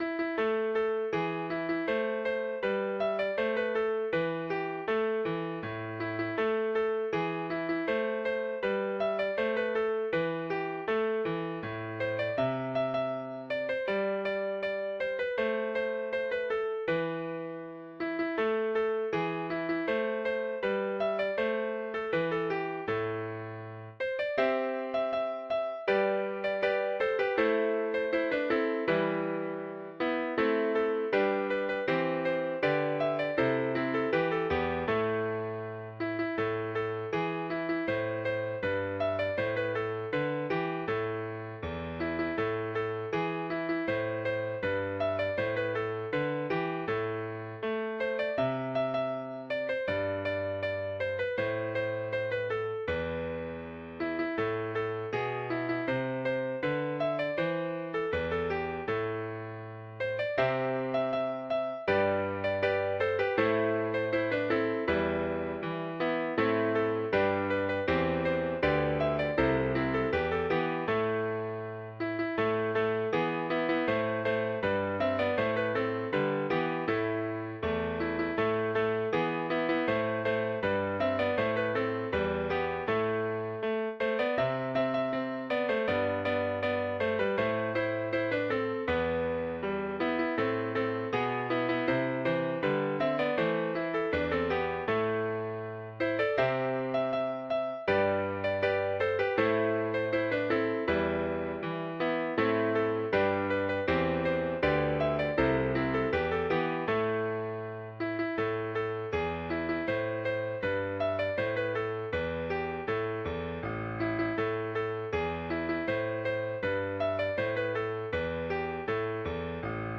Midi File, Lyrics and Information to Rolling Down to Old Maui